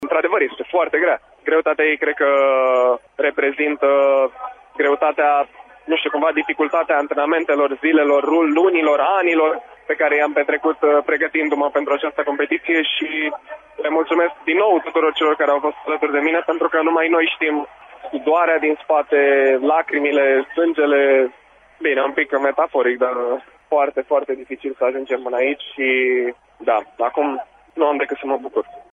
O medalie grea și la propriu și la figurat; așa a caracterizat, aseară, după festivitatea de premiere, aurul olimpic cucerit la 200m liber.
David Popovici a rememorat acest drum incredibil de dificil pentru a trăi acest moment de glorie: